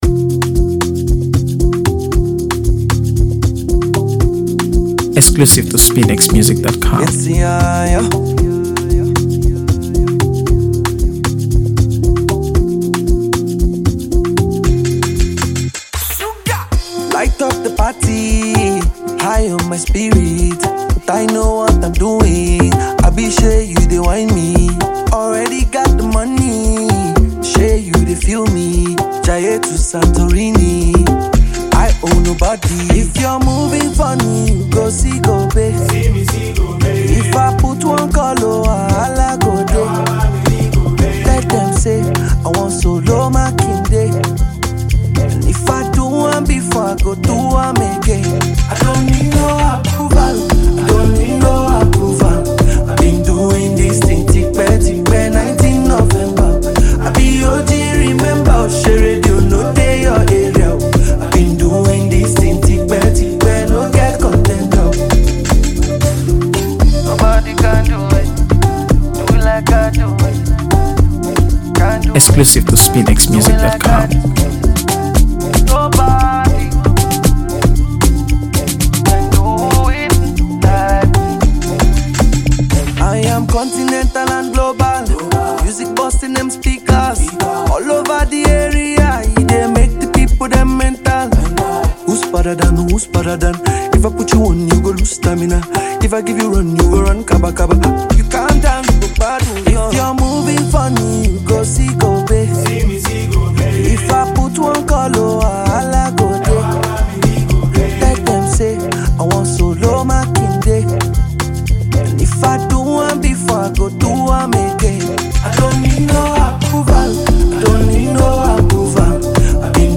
AfroBeats | AfroBeats songs
blending Afrobeat with contemporary melodies.
soulful vocals